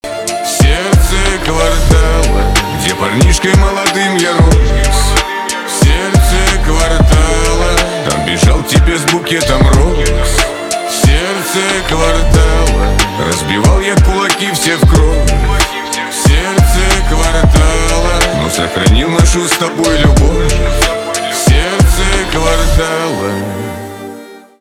шансон
битовые , басы , скрипка